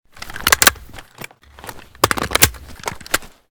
saiga_reload.ogg